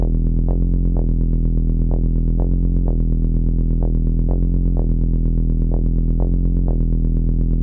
Sustained Tech House Bass Rhythm - EDM - Em - 126.wav
Original creative-commons licensed sounds for DJ's and music producers, recorded with high quality studio microphones.
sustained_tech_house_bass_rhythm_-_edm_-_em_-_126_y3b.ogg